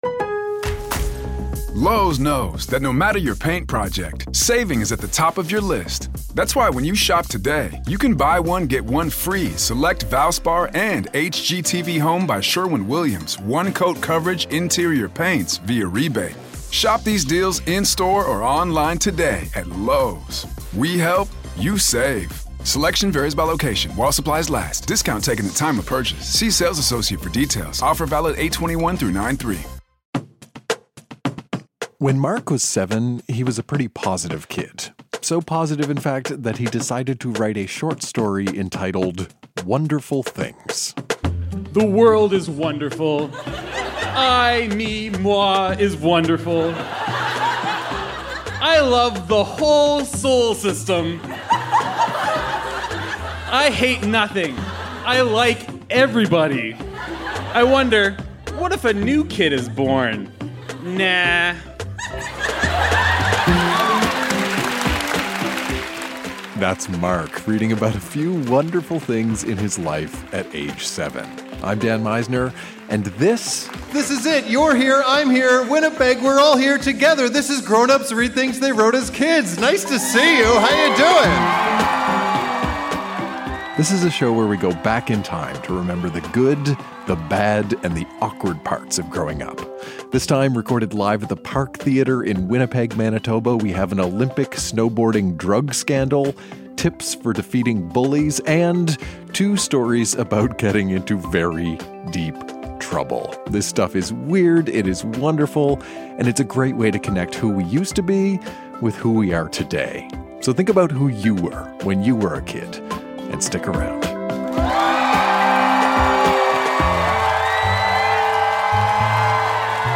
Recorded live at the Park Theatre in Winnipeg, MB.